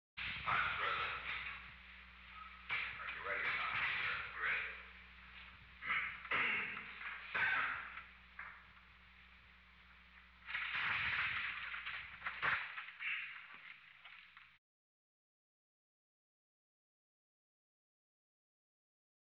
Secret White House Tapes
Location: Oval Office
The President met with an unknown man.